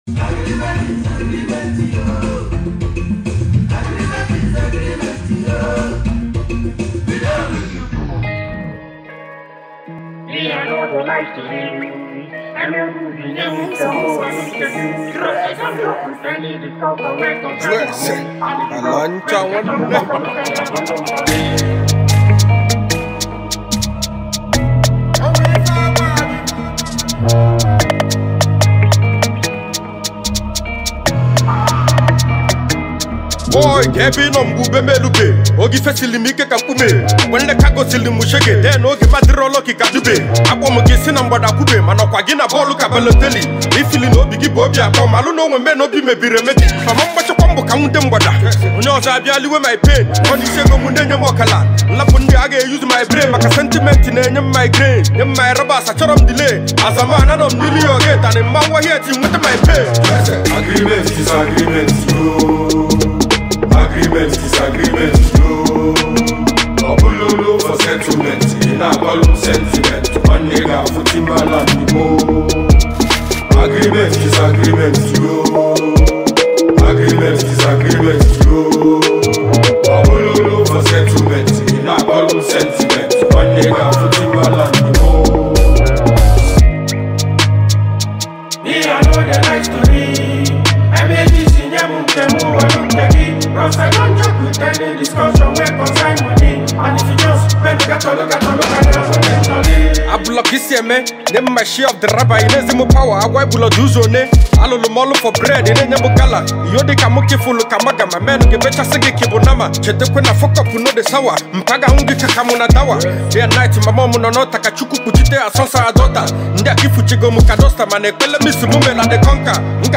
Fast Rising Nigerian rapper